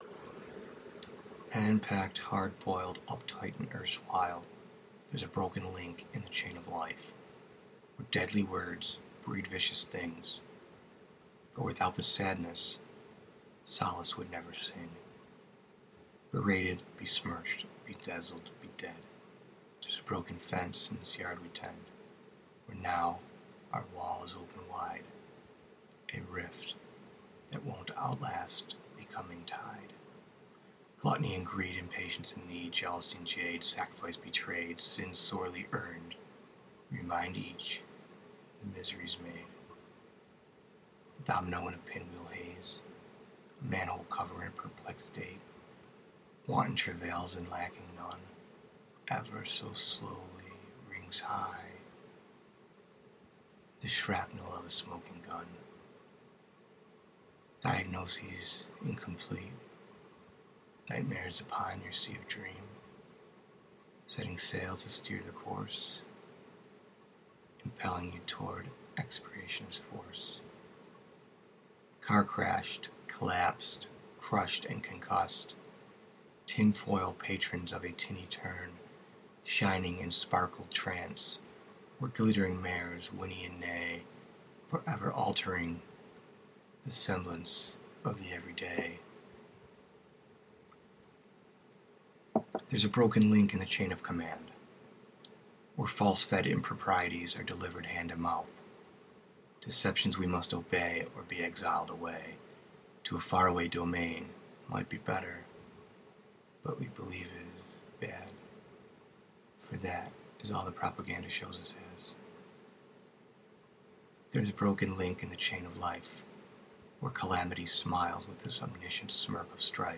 Poetry, Poem, Reading